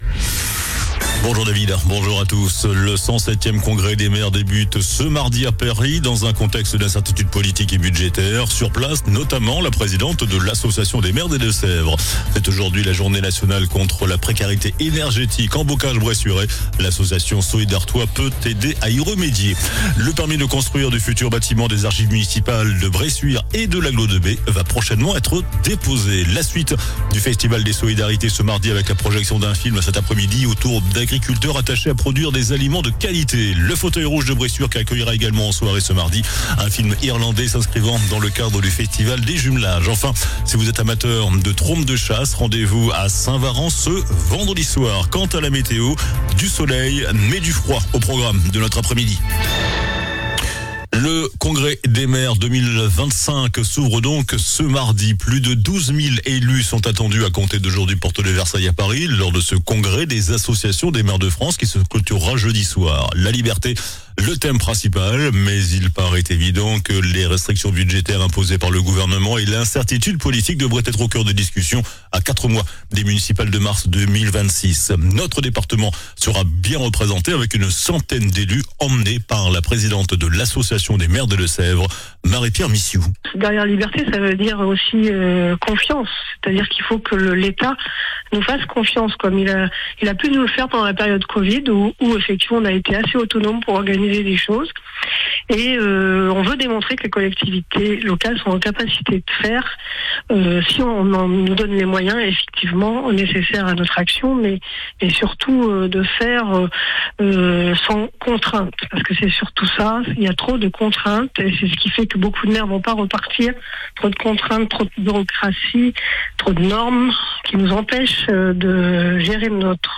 JOURNAL DU MARDI 18 NOVEMBRE ( MIDI )